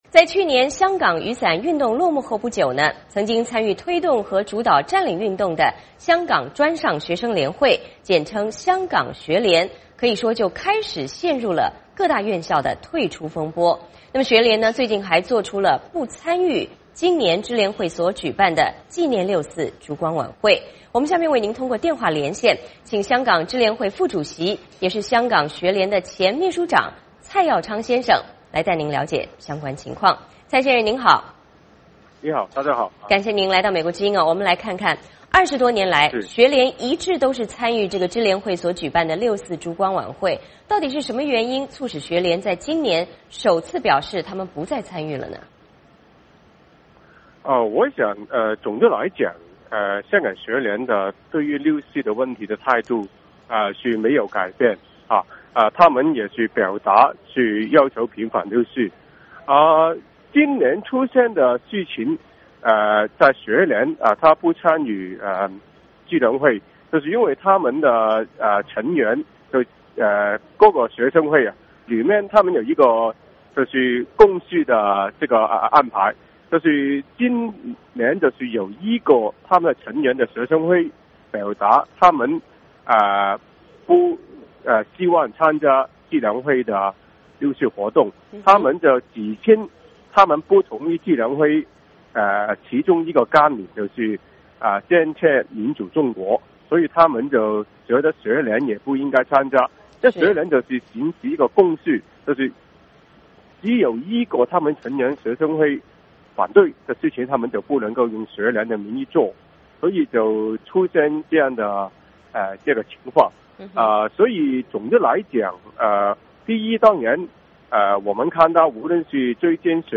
VOA连线：香港学联面临的挑战